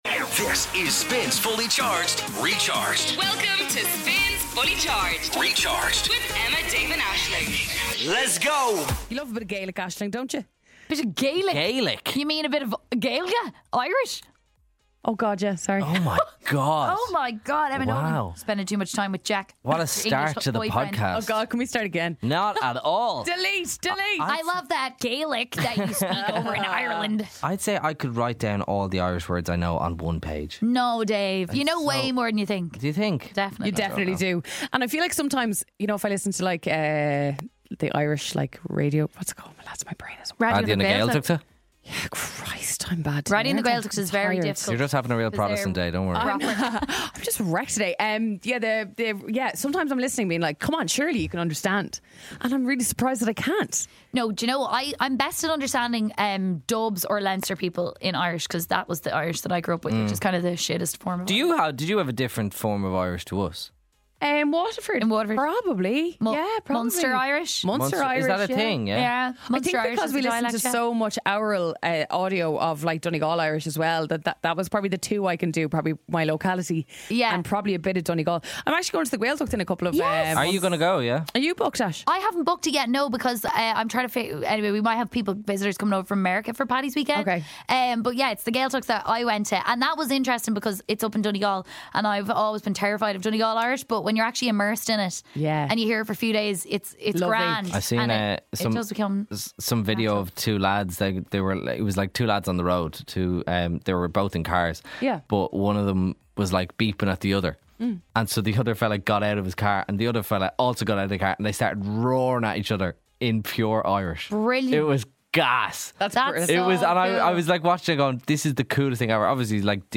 … continue reading 1116 つのエピソード # Comedy # Ireland # SPIN 1038 # Fully Charged Daily